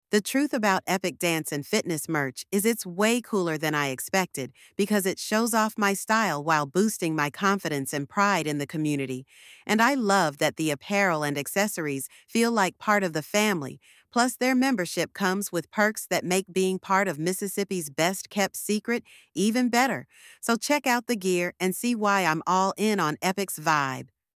Feature the latest Epic branded merchandise with cool music and visuals showcasing apparel and accessories to boost self-esteem and community pride.